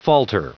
Prononciation du mot falter en anglais (fichier audio)
Prononciation du mot : falter